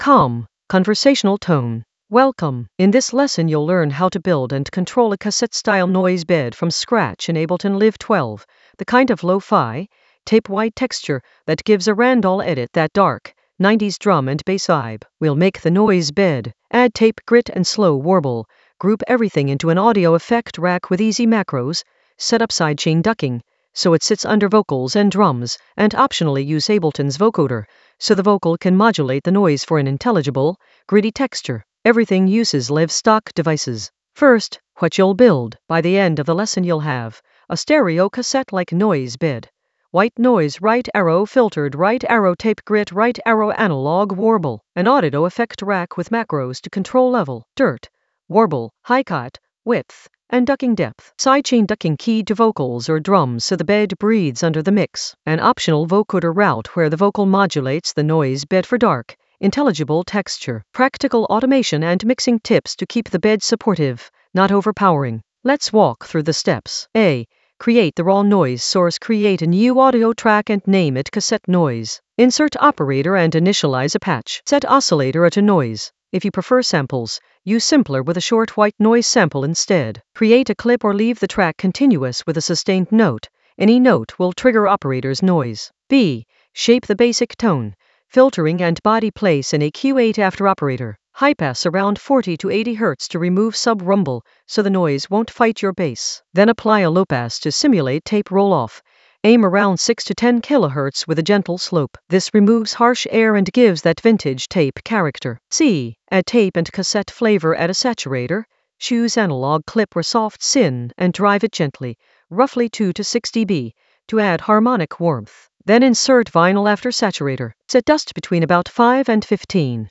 An AI-generated beginner Ableton lesson focused on Randall edit: control a cassette noise bed from scratch in Ableton Live 12 for 90s-inspired darkness in the Vocals area of drum and bass production.
Narrated lesson audio
The voice track includes the tutorial plus extra teacher commentary.